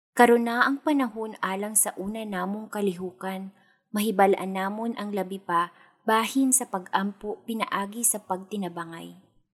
CEBUANO FEMALE VOICES
We use Neumann microphones, Apogee preamps and ProTools HD digital audio workstations for a warm, clean signal path.